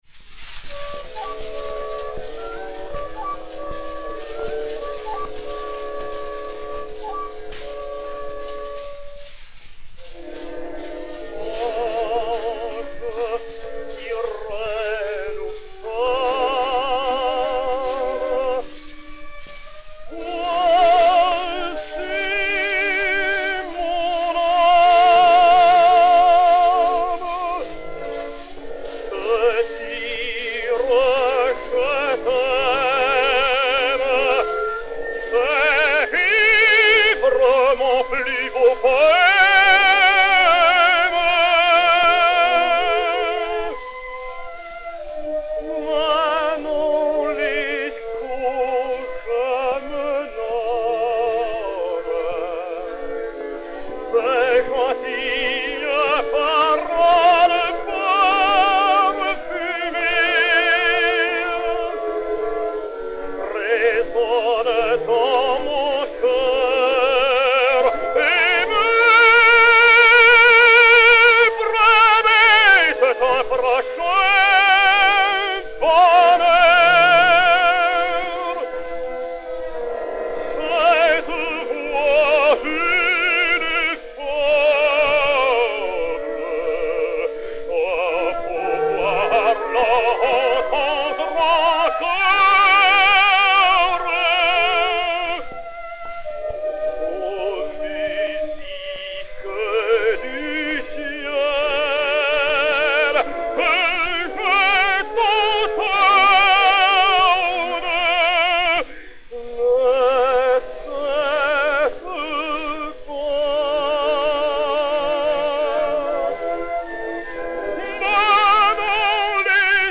Léon Campagnola (Radamès) et Orchestre
Disque Pour Gramophone 032253, mat. 02486v, réédité sur Y 12, enr. à Paris le 28 juin 1912